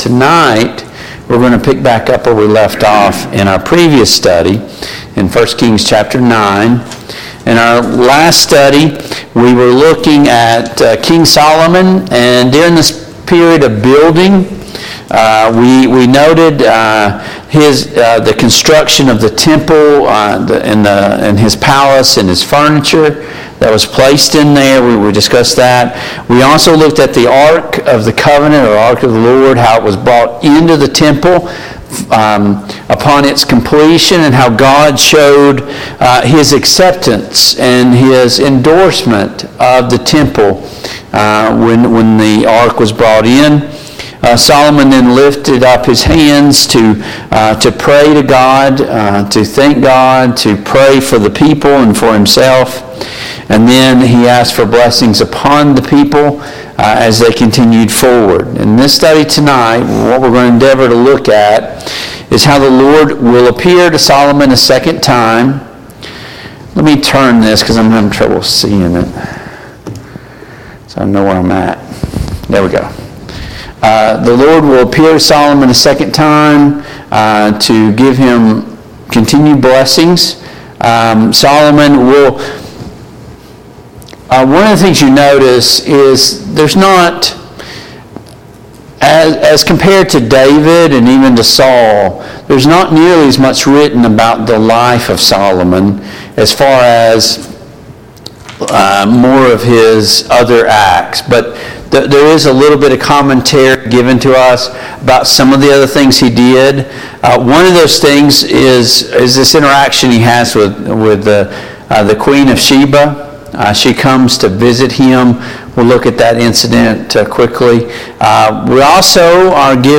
Passage: 1 Kings 9, 1 Kings 10 Service Type: Mid-Week Bible Study